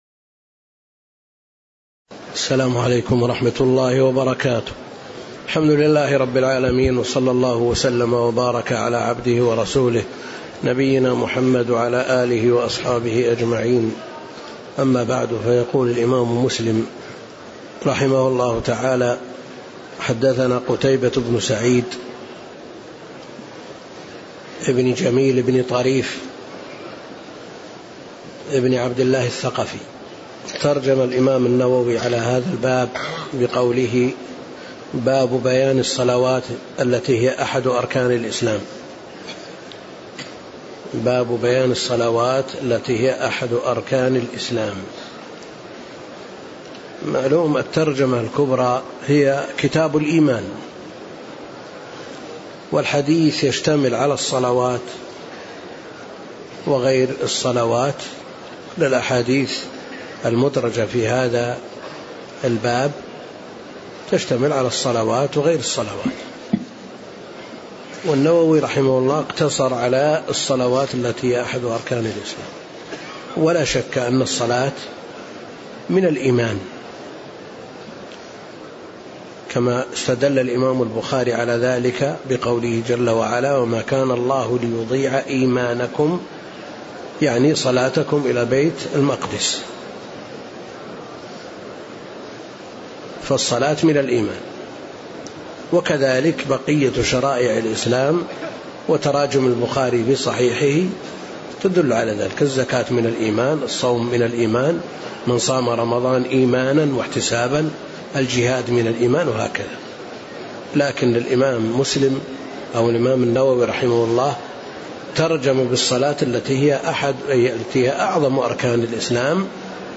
تاريخ النشر ١ محرم ١٤٣٤ المكان: المسجد النبوي الشيخ: فضيلة الشيخ د. عبدالكريم الخضير فضيلة الشيخ د. عبدالكريم الخضير باب بيان الصلوات التي هي أحد أركان الإسلام- من حديث "جاء رجل إلى رسول الله.."